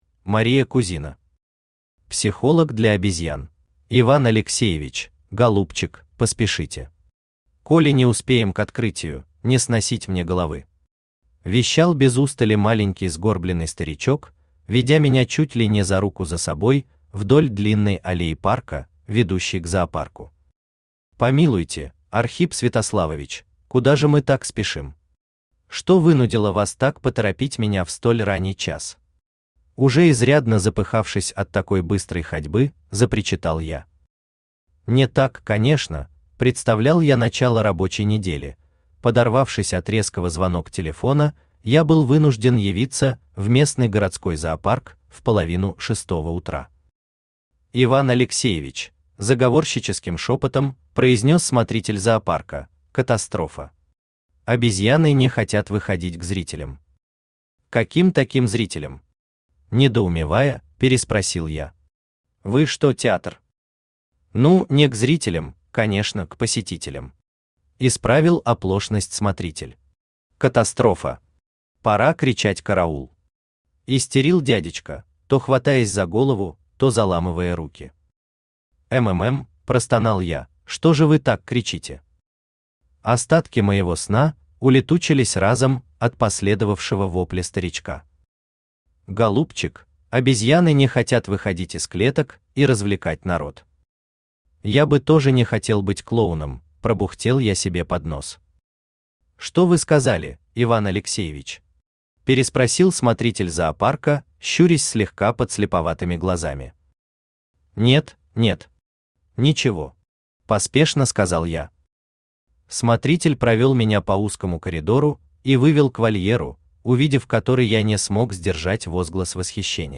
Аудиокнига Психолог для обезьян | Библиотека аудиокниг
Aудиокнига Психолог для обезьян Автор Мария Кузина Читает аудиокнигу Авточтец ЛитРес.